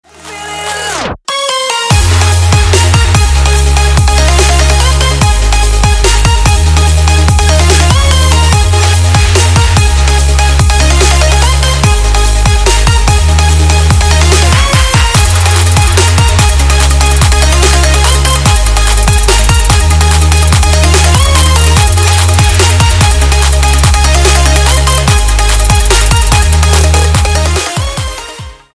• Качество: 256, Stereo
dance
Electronic
Trap
club